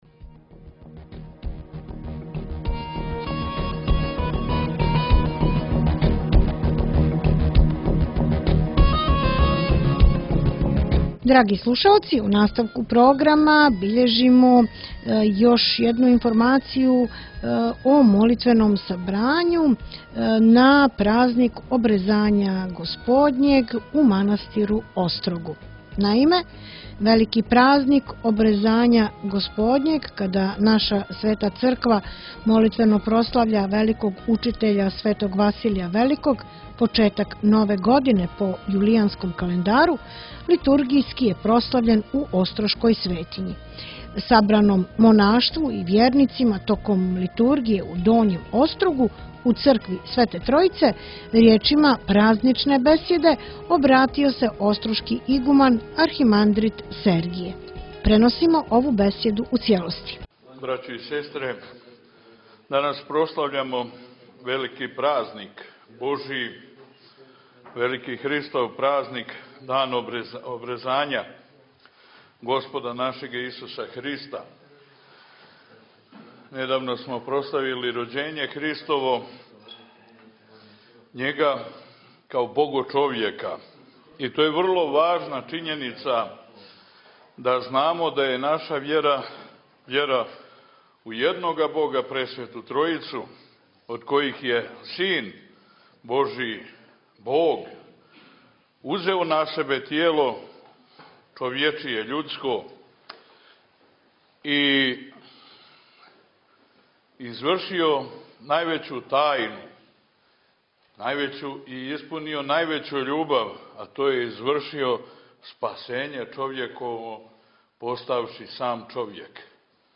besjeda na Vasilija Velikog